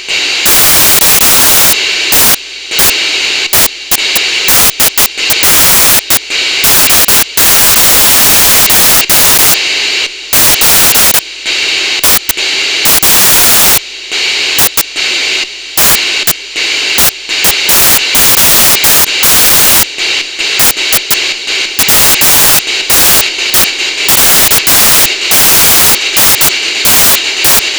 Increasing the volume control slightly and the unit starts to chuff , adjusting the other control increases the speed of the chuff thus by a combination of both you can get from just a hiss to full speed .